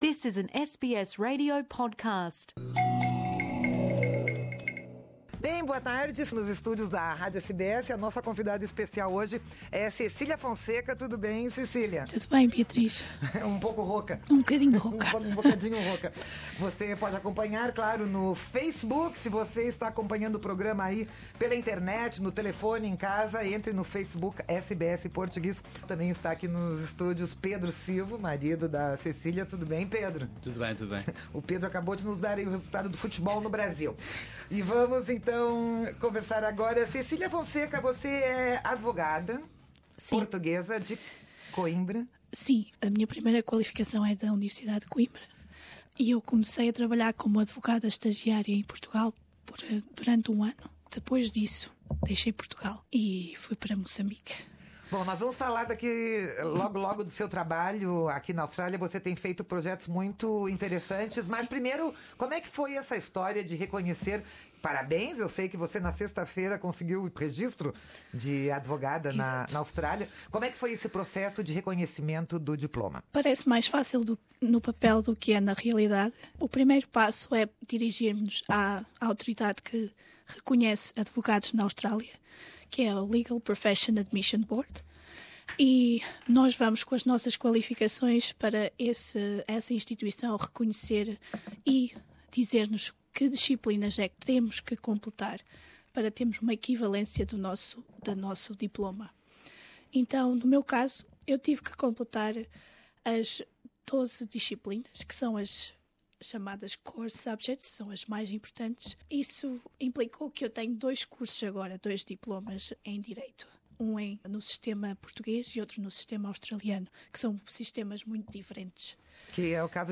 Nessa entrevista ela fala de seu trabalho em defesa da biodiversidade e dos tubarões da Grande Barreira de Corais, o maior parque marinho do planeta.